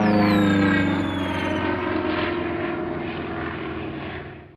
planeShotDown.ogg